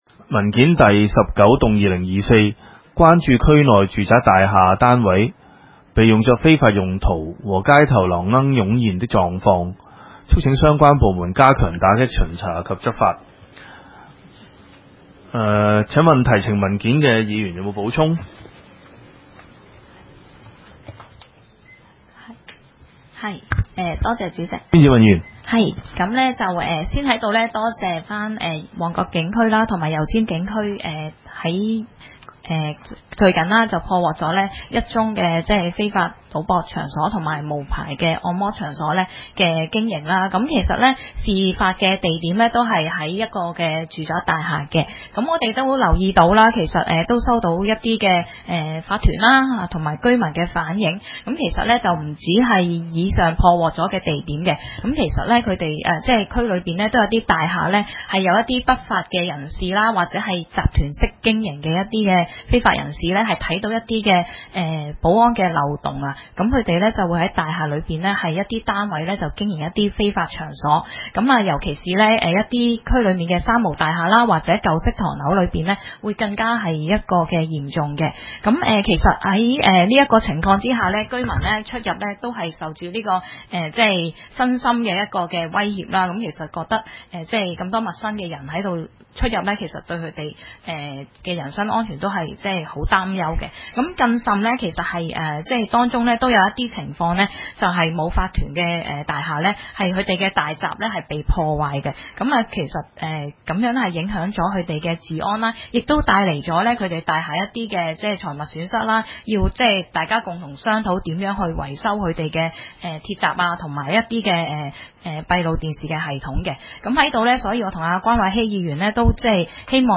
會議的錄音記錄